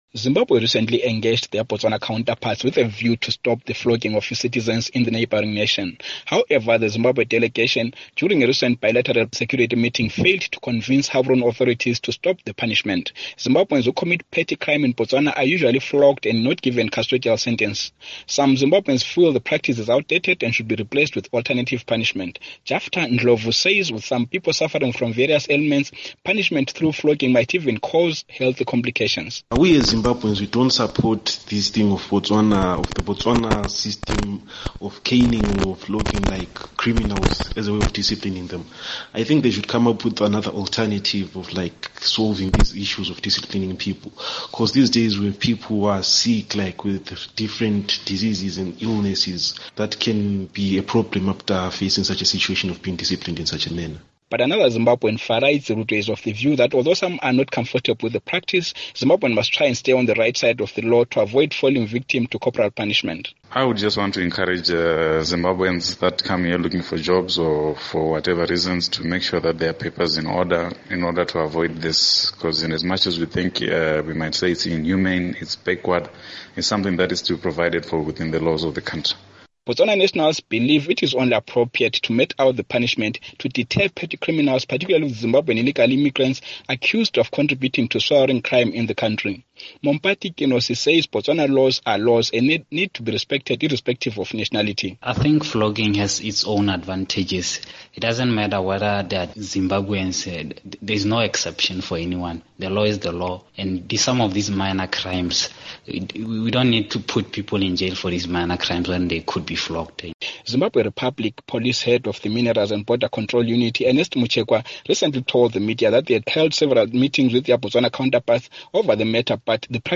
Report on Botswana Floggings